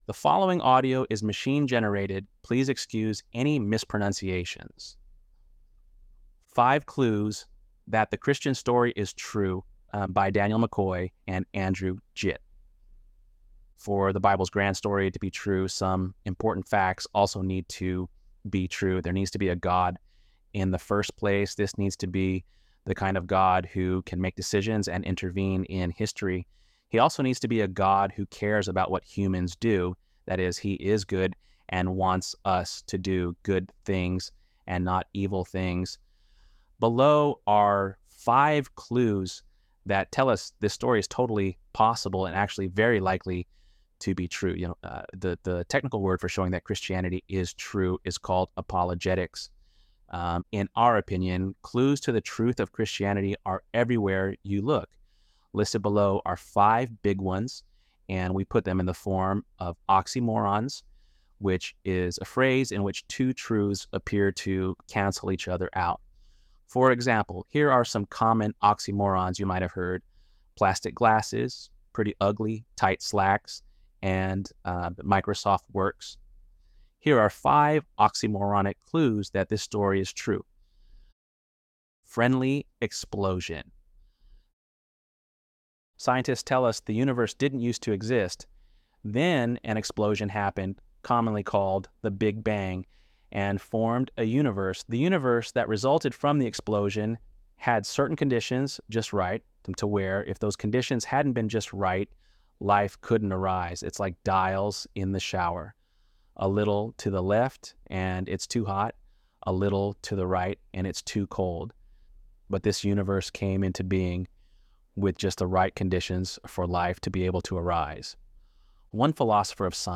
ElevenLabs_Untitled_project-44.mp3